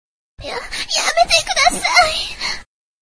nibutani shinka yamete kudasai Meme Sound Effect
Category: Anime Soundboard